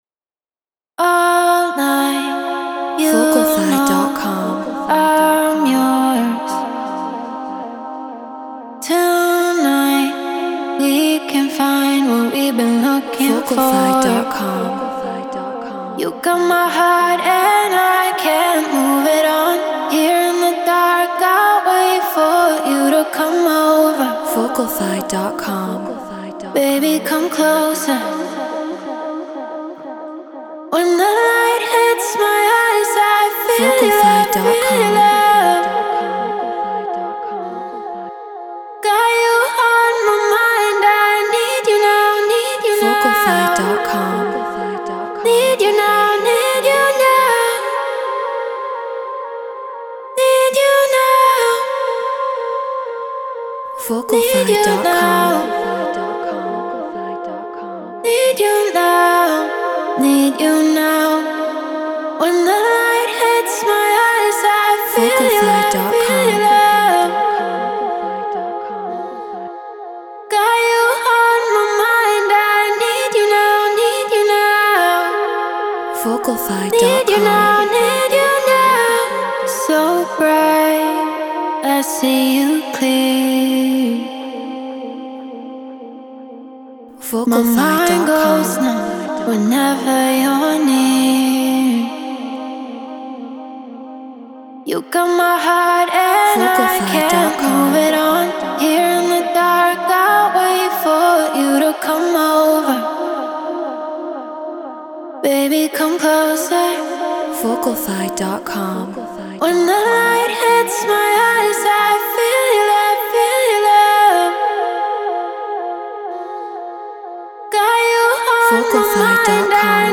Afro House 122 BPM A#min
Shure KSM 44
Treated Room